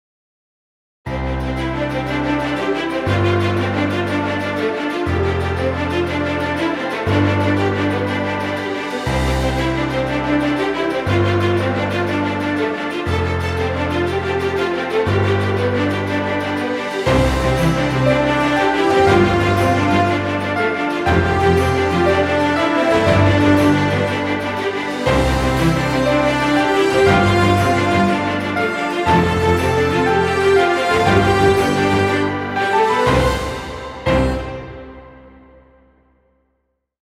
Epic music, exciting intro, or battle scenes.